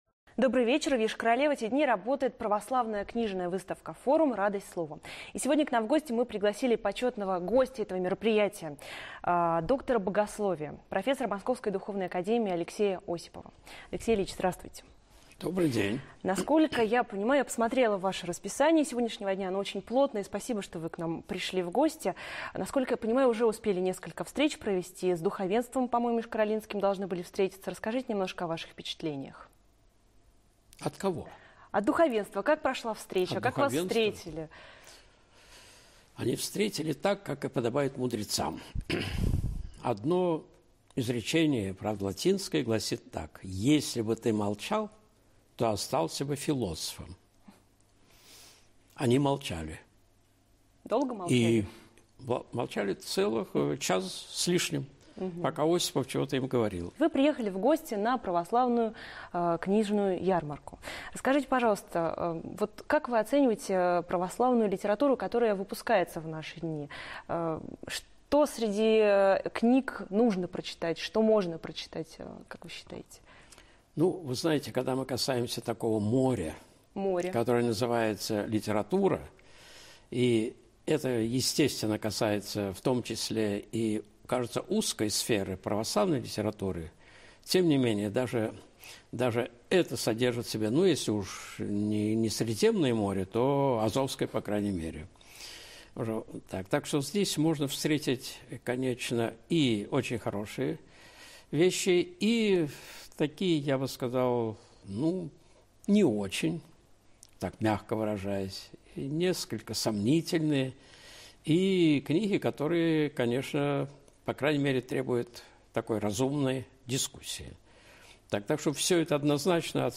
Интервью (ТК «Россия 24».